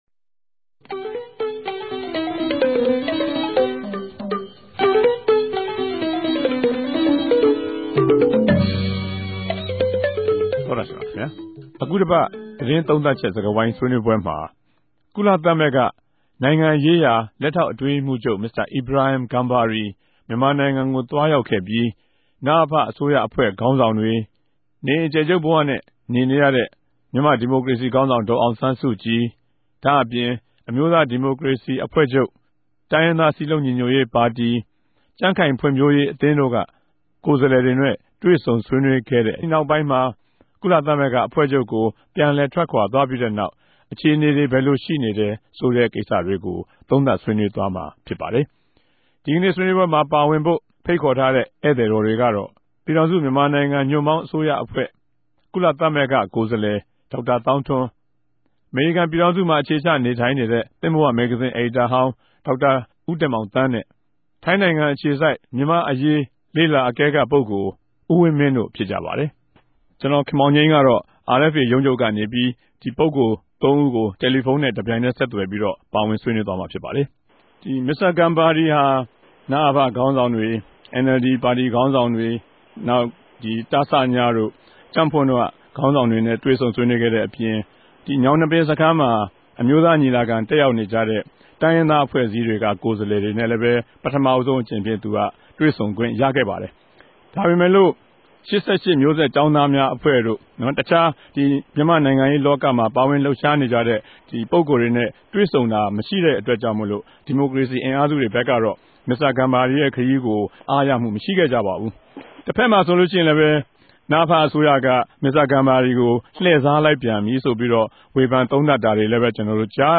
တပတ်အတြင်း သတင်းသုံးသပ်ခဵက် စကားဝိုင်း (၂၀၀၆ ိံိုဝင်ဘာလ ၂၆ရက်)
ဝၝရြင်တန်္ဘမိြႚ RFA စတူဒီယိုထဲကနေ